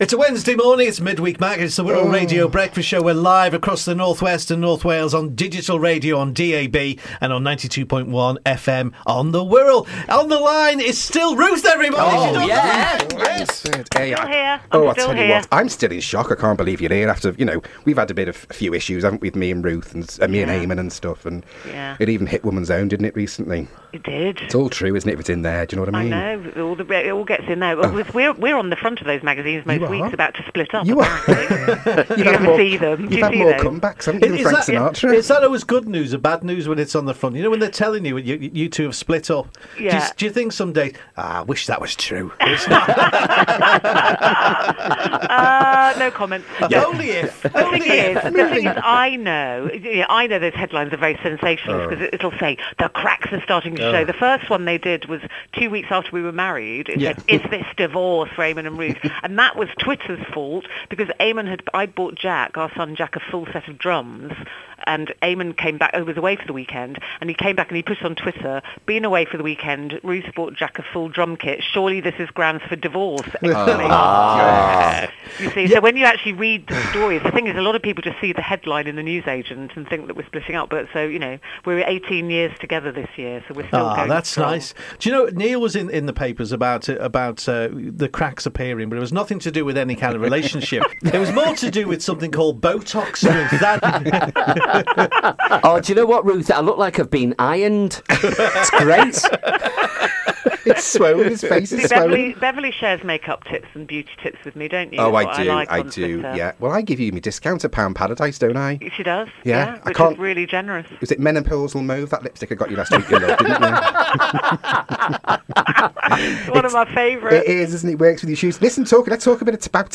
part of the Wirral Radio Breakfast Show.